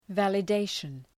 Προφορά
{,vælə’deıʃən}